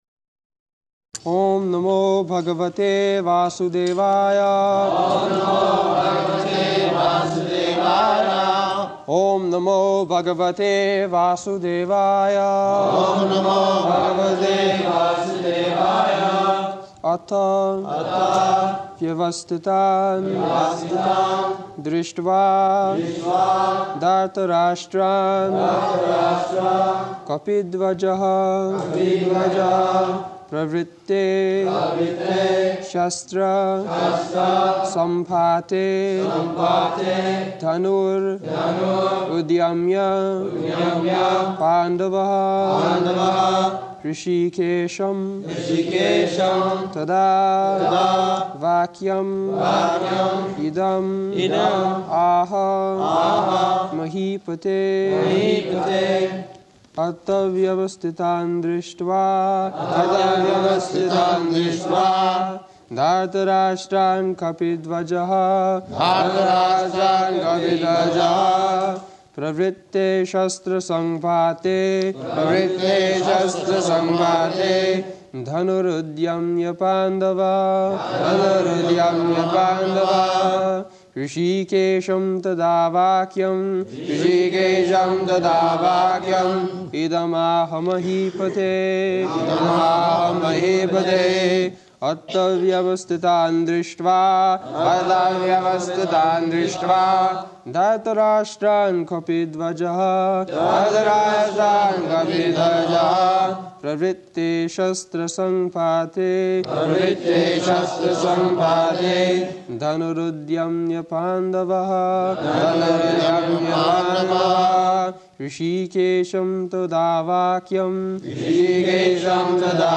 July 17th 1973 Location: London Audio file
[Prabhupāda and devotees repeat] [leads chanting of verse]